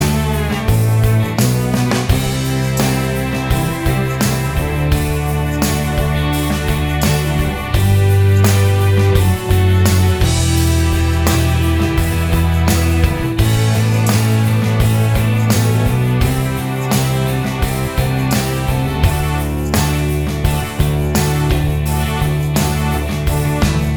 Minus Lead And Solo Indie / Alternative 5:49 Buy £1.50